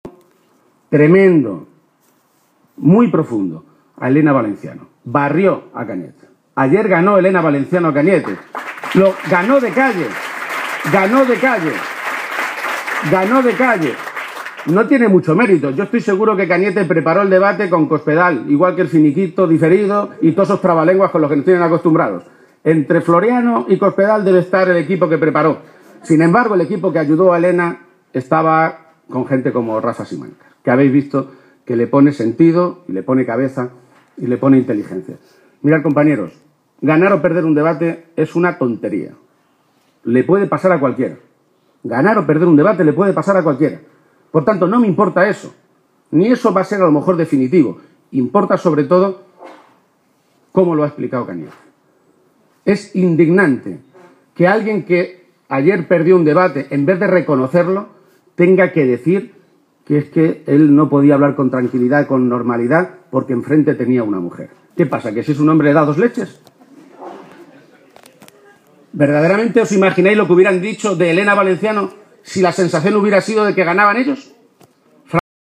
El secretario general del PSOE de Castilla-La Mancha, Emiliano García-Page, ha protagonizado esta tarde un acto electoral en la localidad toledana de Illescas en el que ha dicho que la campaña empieza a ir muy bien para el PSOE y ha animado a oos militantes, simpatizantes y votantes socialistas a seguir “porque a nosotros no nos van a quitar la moral Gobiernos como los de Rajoy y Cospedal, que no tienen moral”.